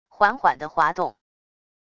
缓缓的滑动wav音频